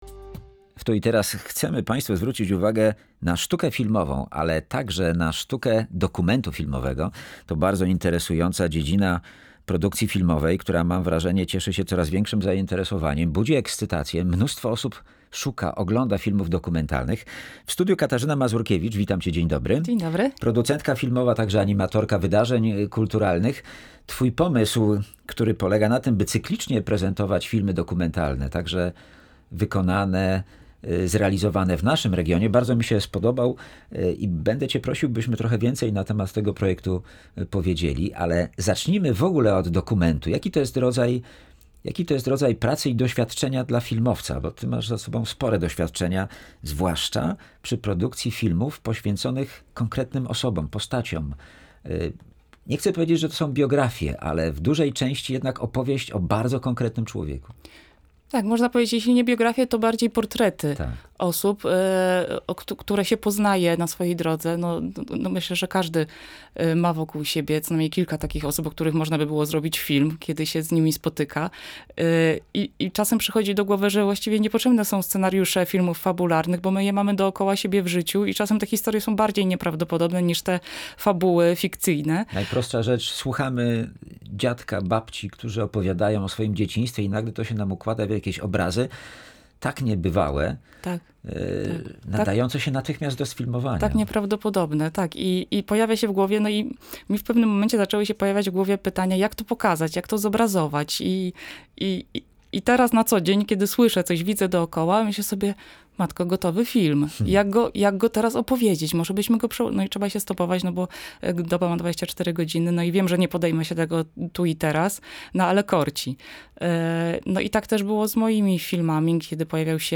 Projekcje odbywać się będą w Miejskim Domu Kultury w Łańcucie. Jakich filmów można się spodziewać?